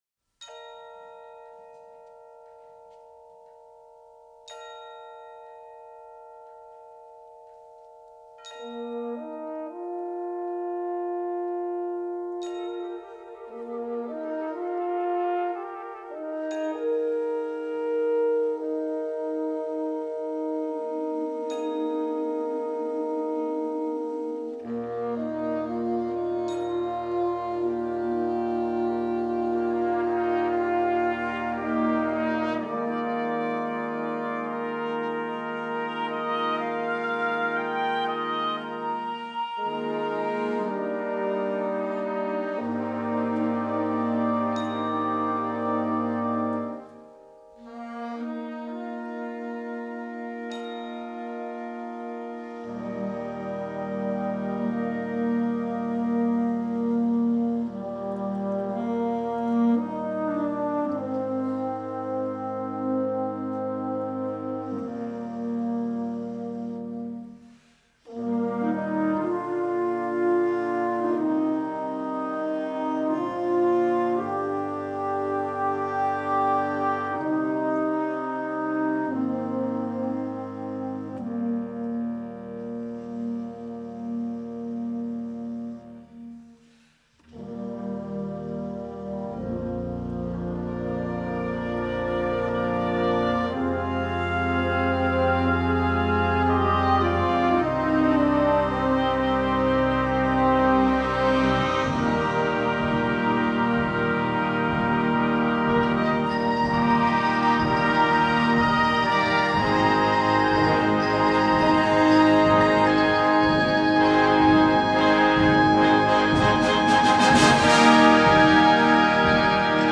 Concert Band